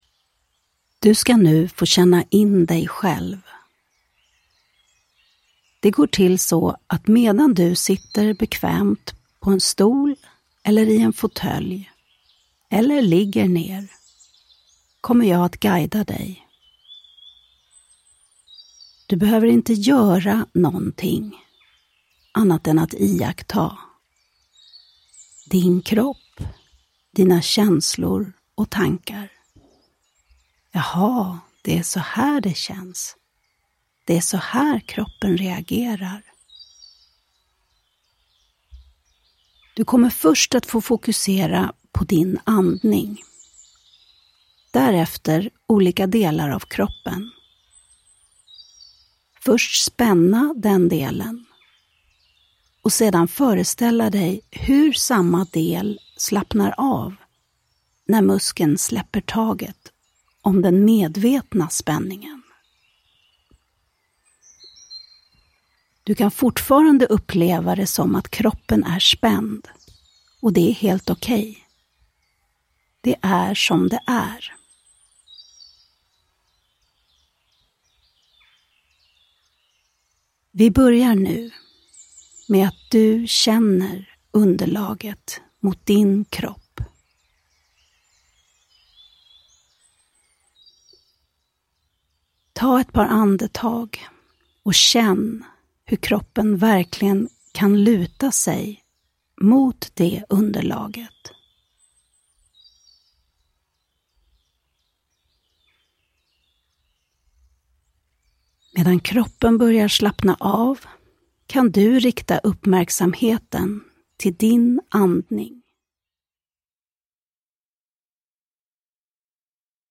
Ljudbok
-en meditation för att känna in dig själv